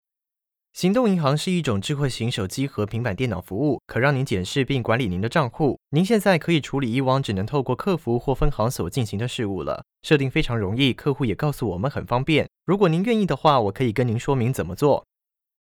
Traditional (Tayvan) Seslendirme
Erkek Ses